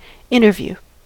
interview: Wikimedia Commons US English Pronunciations
En-us-interview.WAV